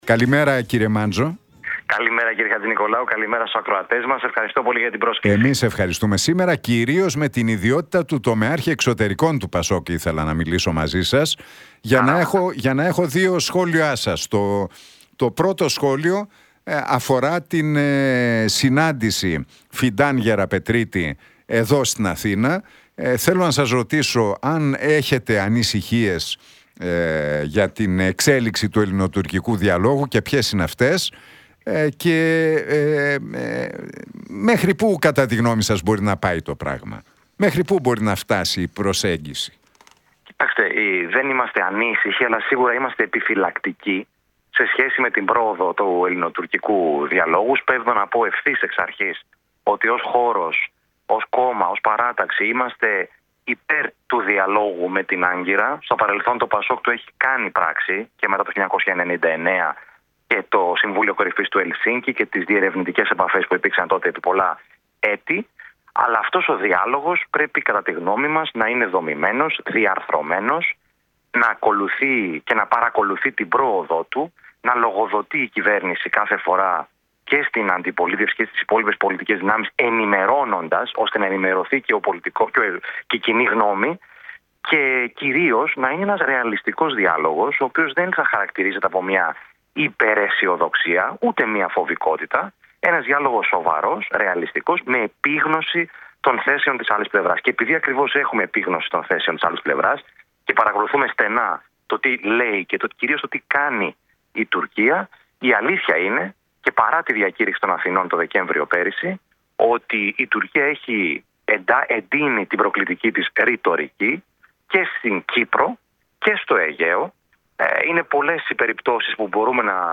«Δεν είμαστε ανήσυχοι αλλά σίγουρα είμαστε επιφυλακτικοί σε σχέση με την πρόοδο του ελληνοτουρκικού διαλόγου. Ως κόμμα είμαστε υπέρ του διαλόγου με την Άγκυρα» δήλωσε ο κοινοβουλευτικός εκπρόσωπος του κόμματος και τομεάρχης Εξωτερικών του ΠΑΣΟΚ ΚΙΝΑΛ, Δημήτρης Μάντζος στην εκπομπή του Νίκου Χατζηνικολάου στον Realfm 97,8.